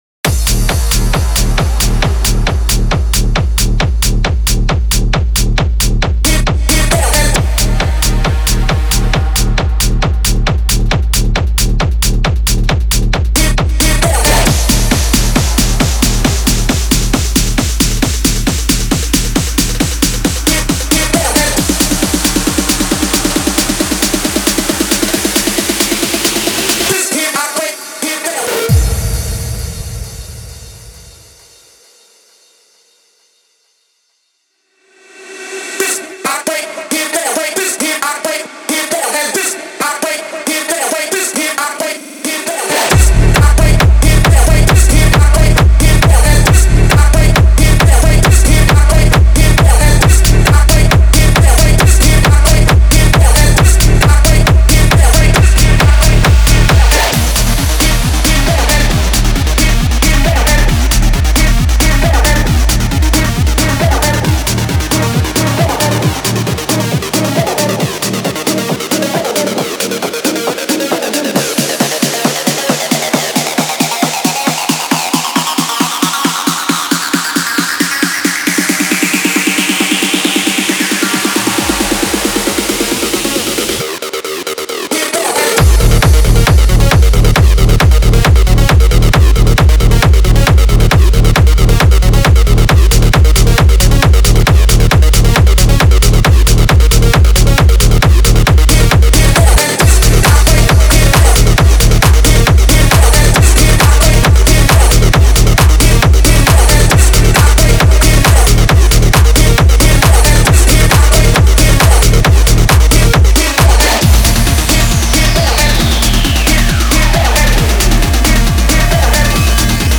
Type: Serum Samples